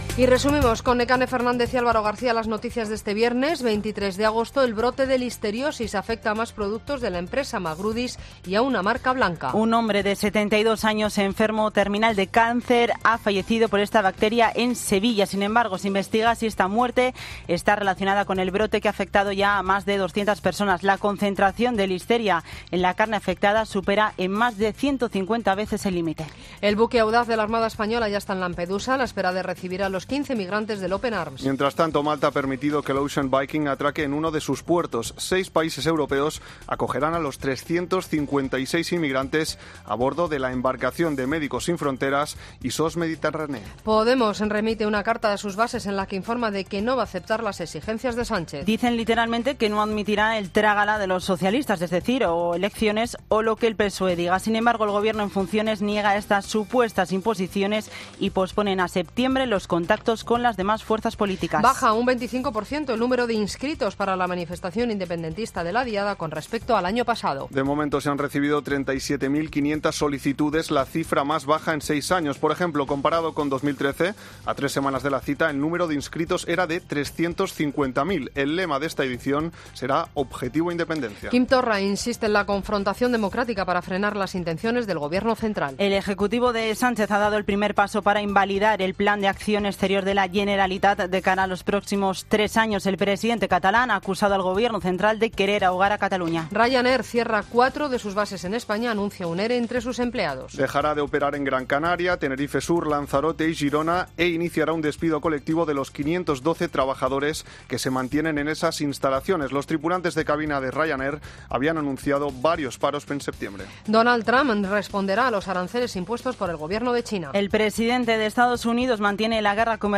Boletín de noticias de COPE del 23 de agosto de 2019 a las 20.00 horas